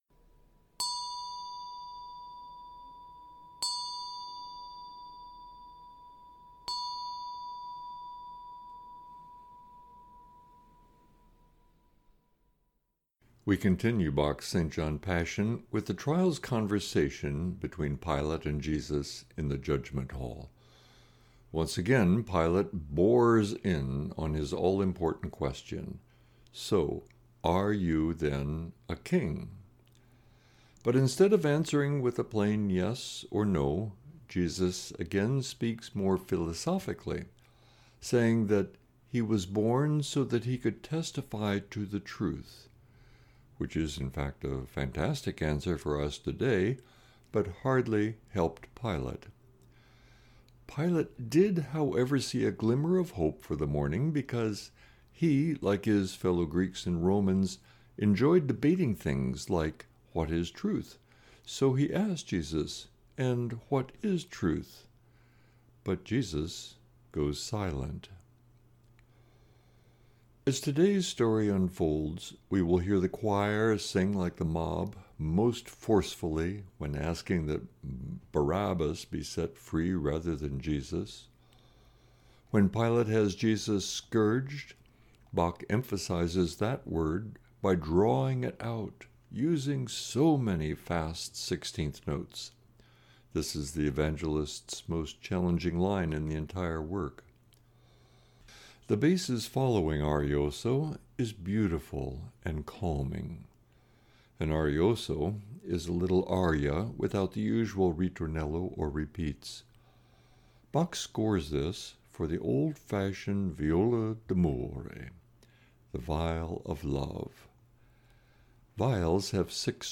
Meditation - Point Grey Inter-Mennonite Fellowship